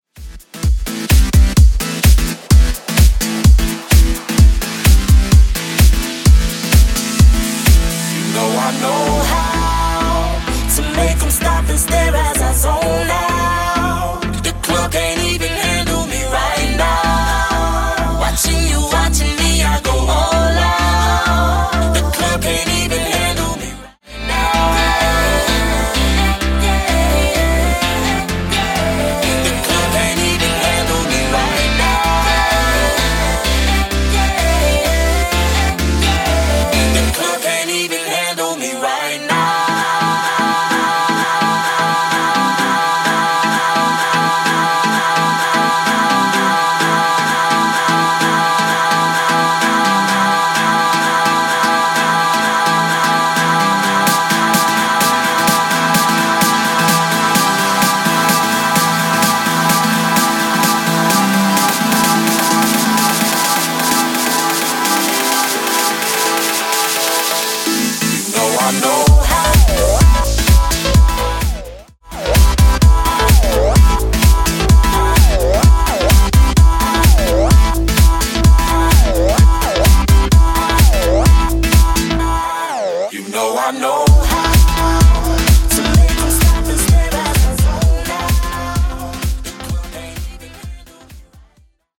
Genres: RE-DRUM , TOP40
Clean BPM: 126 Time